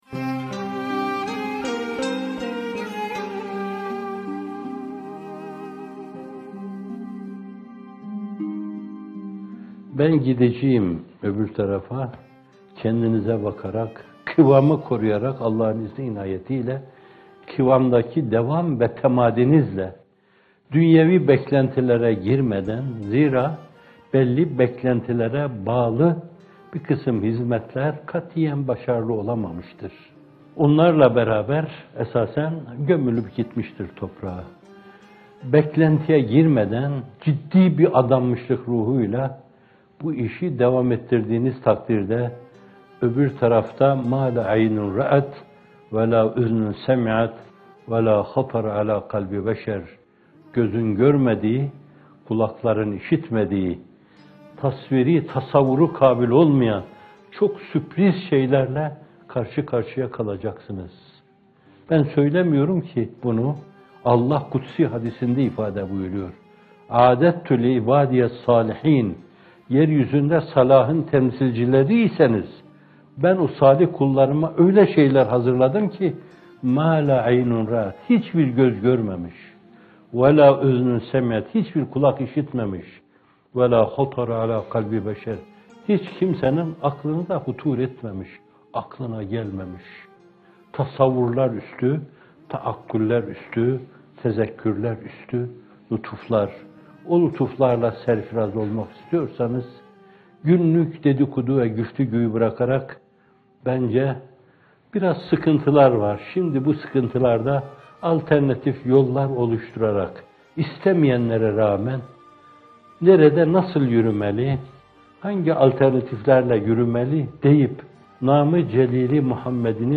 Muhterem Fethullah Gülen Hocaefendi’nin 25 Aralık 2015 tarihinde yayınlanan sohbetinden bu bölümü, günümüze ışık tutması açısından bir kez daha istifadenize sunuyoruz.